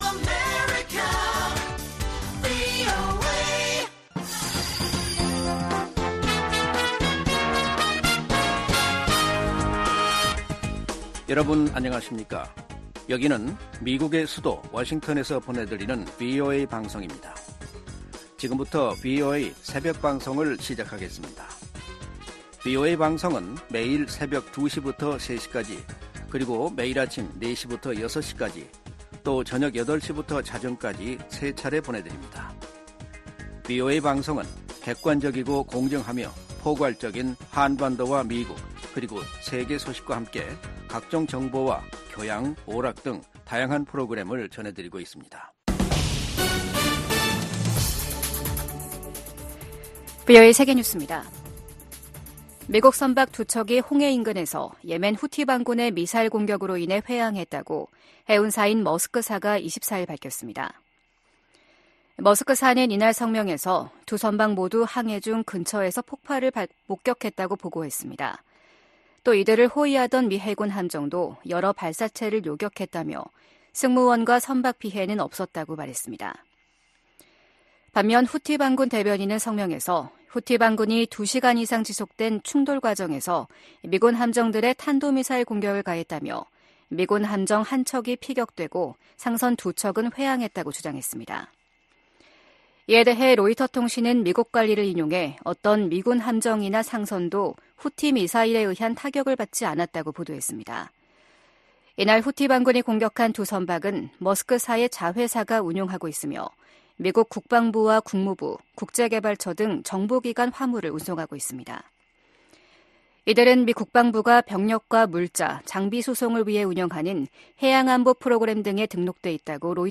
VOA 한국어 '출발 뉴스 쇼', 2024년 1월 26일 방송입니다. 북한이 신형 전략순항미사일을 첫 시험발사했다고 밝혔습니다.